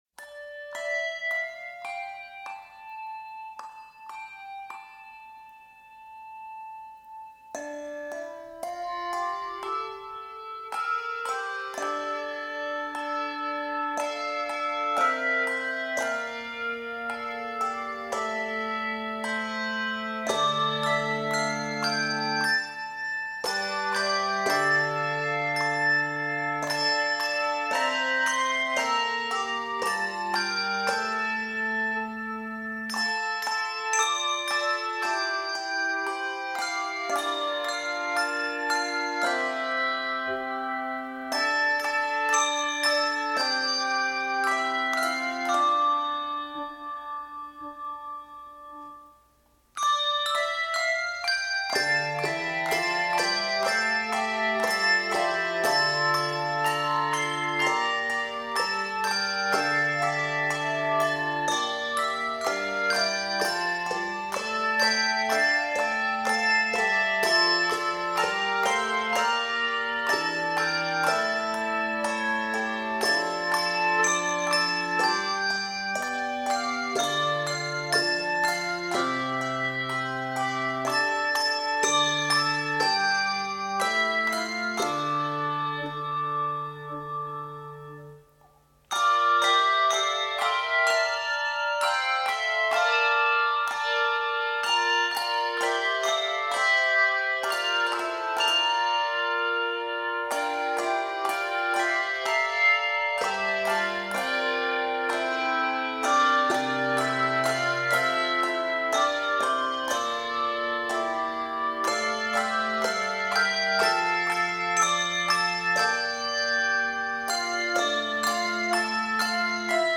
Key of d minor.